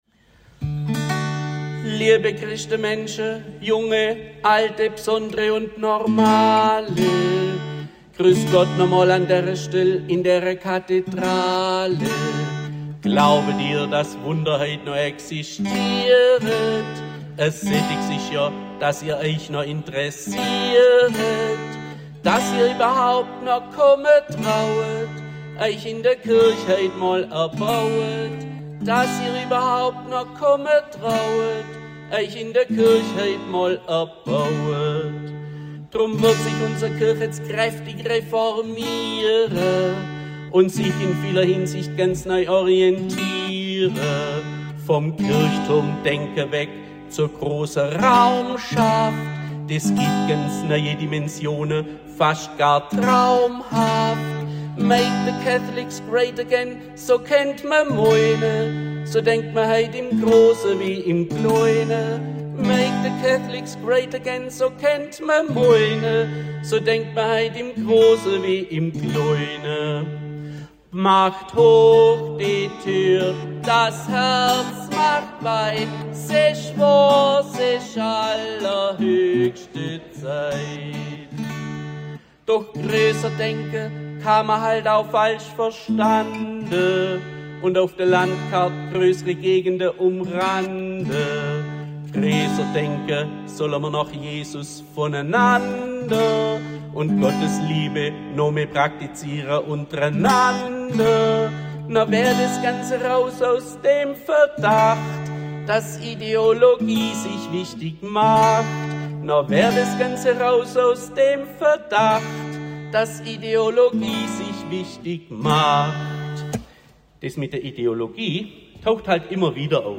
Die gesungene Fasnetspredigt
Fasnetspredigt_2026.mp3